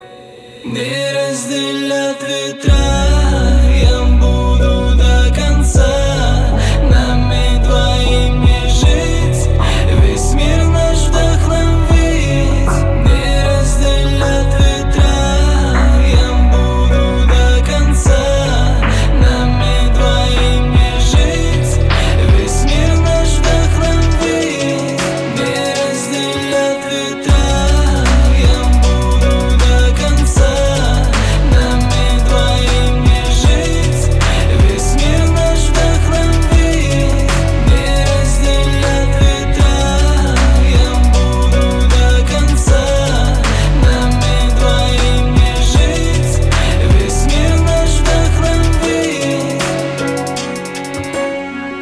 • Качество: 320, Stereo
лирика
русский рэп